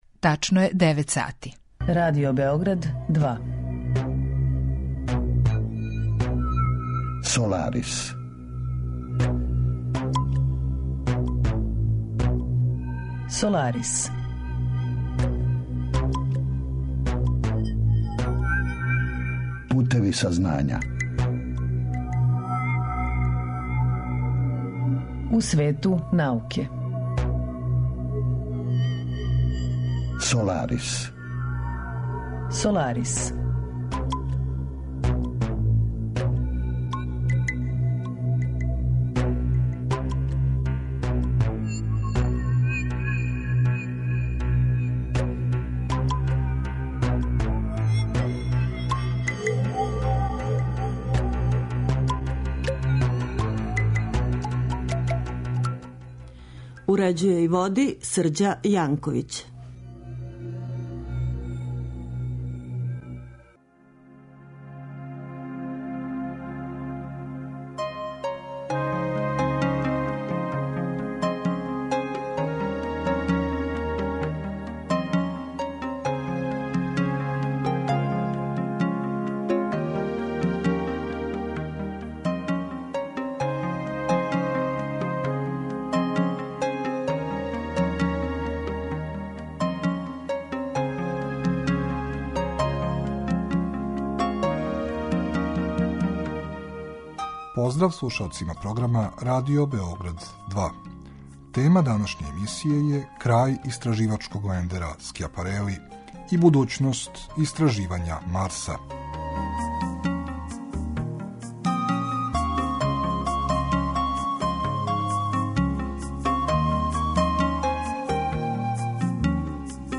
Саговорници у емисији обухватају научнике који се баве истраживањима из различитих области, од носилаца врхунских резултата и признања до оних који се налазе на почетку свог научног трагања, али и припаднике разноврсних професија који су у прилици да понуде релевантна мишљења о одговарајућим аспектима научних подухвата и науке у целини.